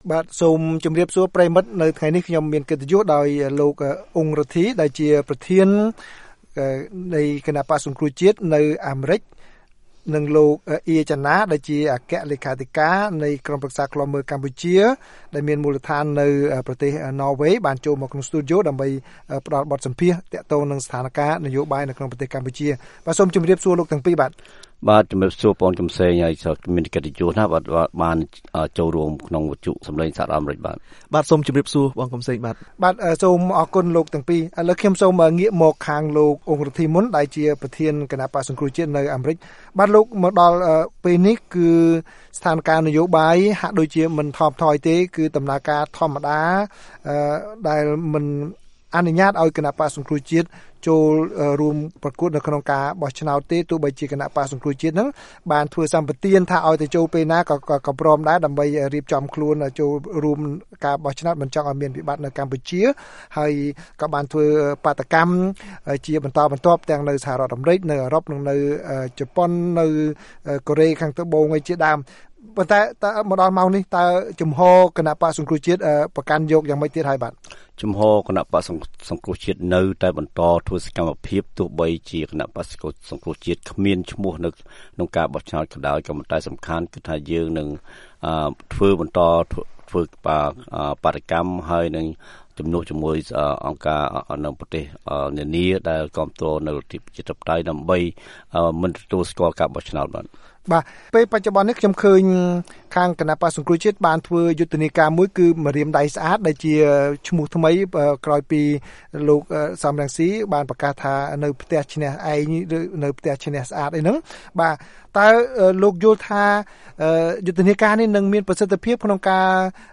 បទសម្ភាសន៍VOA៖ បក្សសង្គ្រោះជាតិនៅសហរដ្ឋអាមេរិកគ្រោងធ្វើបាតុកម្មថ្ងៃទី២៨និង២៩